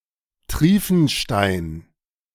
Markt Triefenstein (German: [ˈtʁiːfn̩ˌʃtaɪ̯n]
De-Triefenstein.ogg.mp3